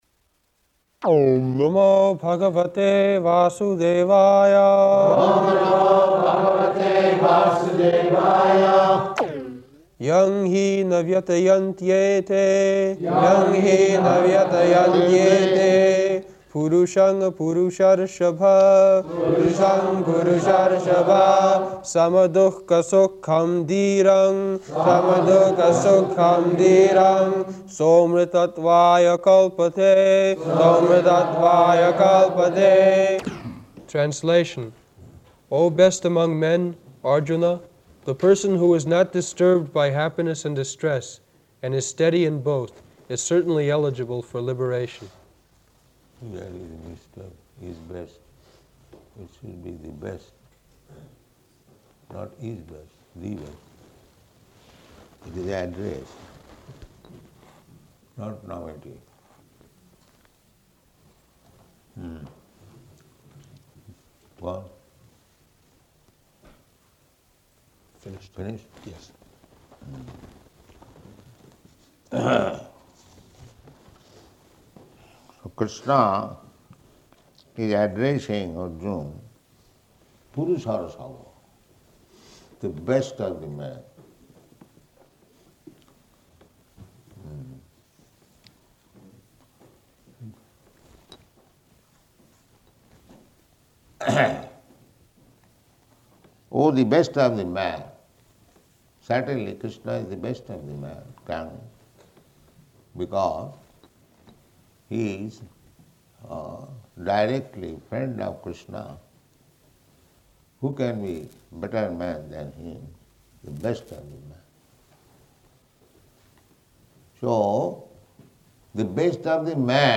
August 21st 1973 Location: London Audio file
[Prabhupāda and devotees repeat]